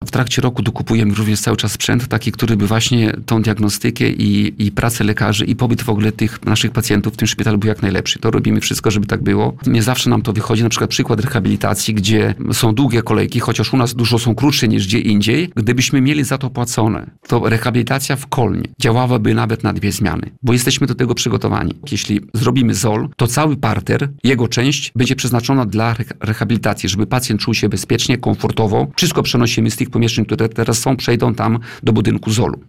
Jak podkreślał na antenie Radia Nadzieja Starosta Kolneński Tadeusz Klama – szpital cały czas się rozwija i stawia na komfort pacjentów, lecz bywają również przeszkody, jak chociażby długie kolejki na rehabilitacje.